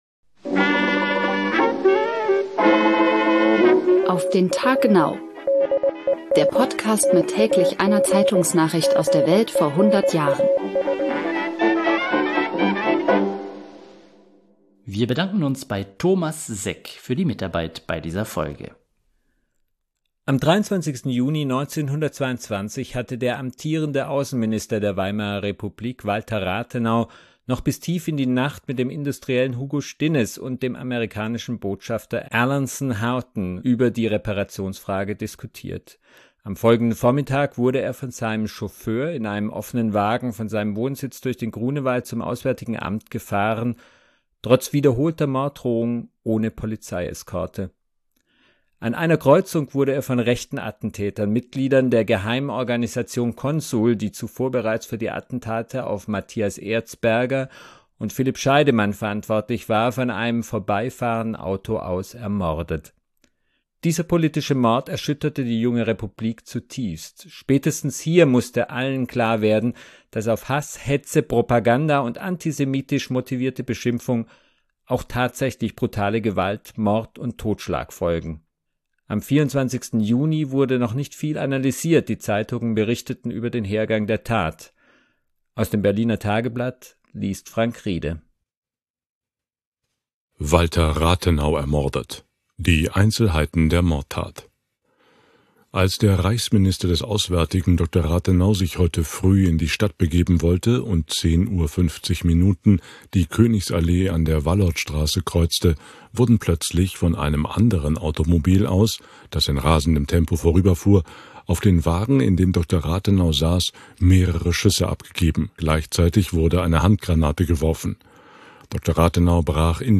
Aus dem Berliner Tageblatt liest